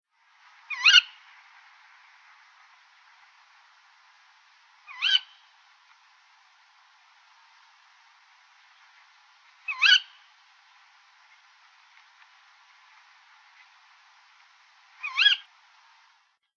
Waldkauz